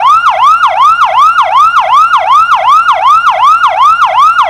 Suono Allarme (wav/mp3)
MOF-suono.mp3